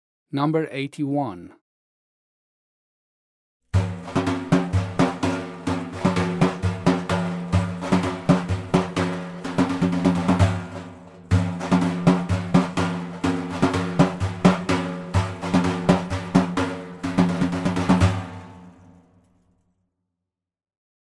Daf and Taraneh Lesson Sound Files